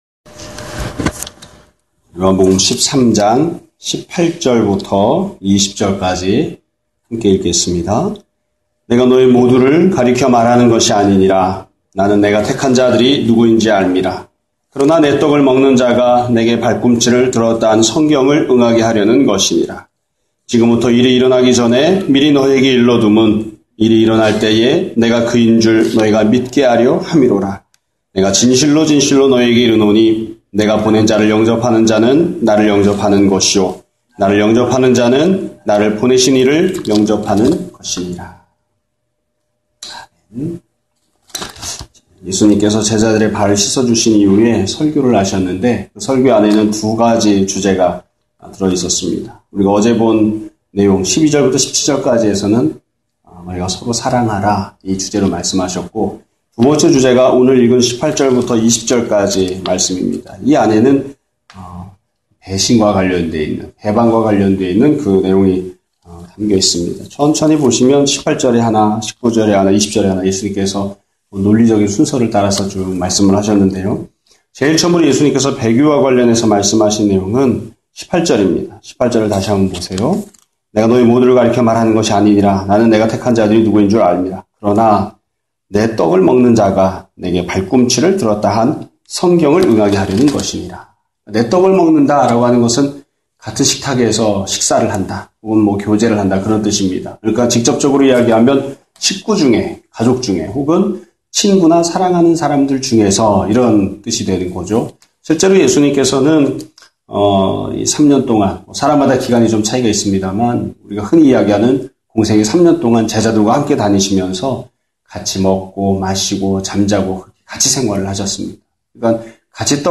2017년 1월6일(금요일) <아침예배> 설교입니다.